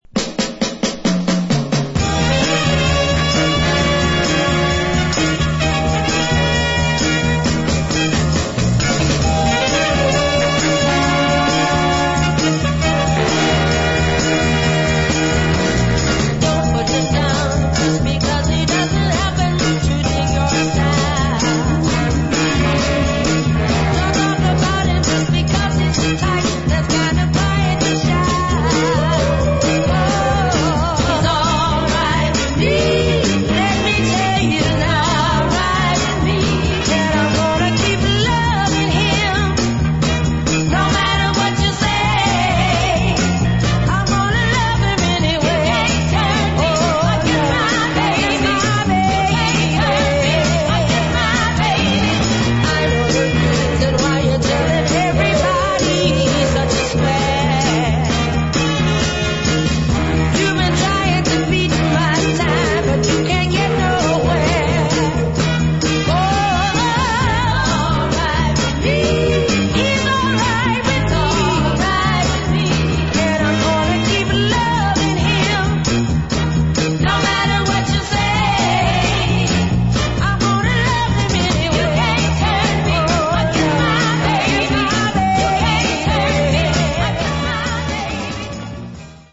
70's soul
One of THE very best Northern Soul dancers
Unreleased Northern Soul dancer